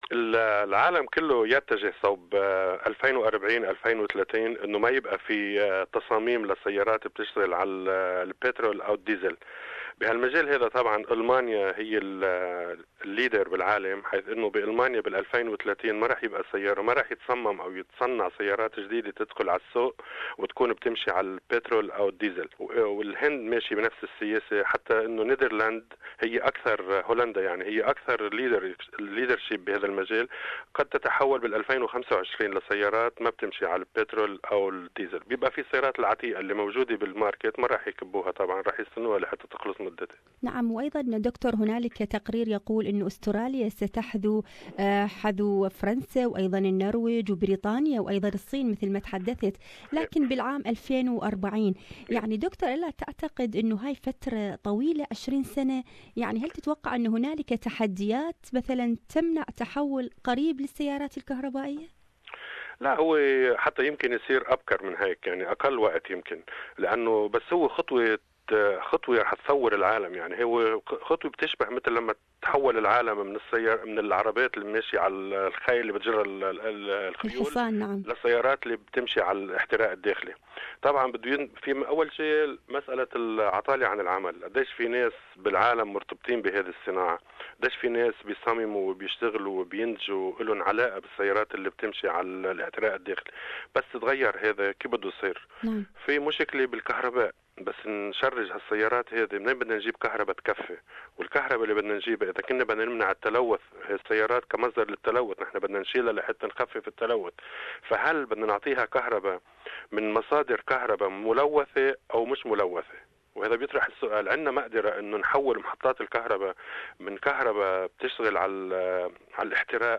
تحدثنا الى المهندس الكهربائي